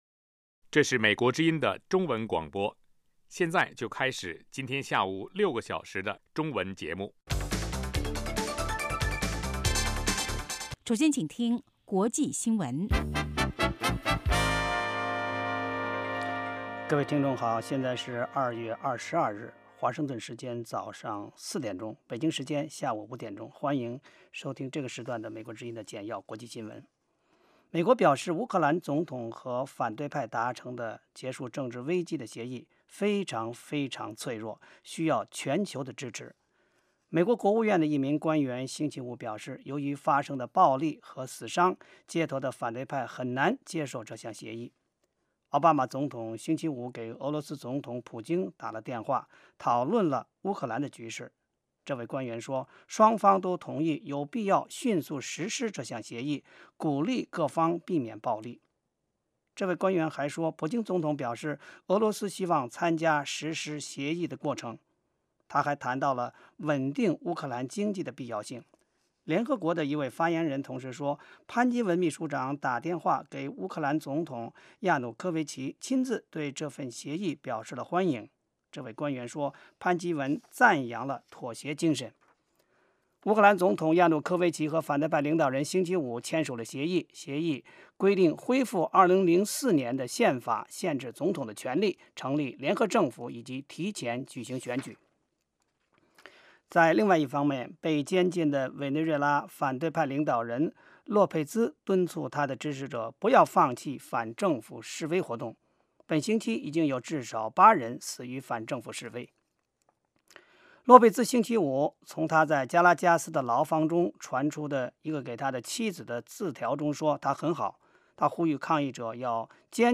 晚5-6点广播节目
国际新闻 英语教学 社论 北京时间: 下午5点 格林威治标准时间: 0900 节目长度 : 60 收听: mp3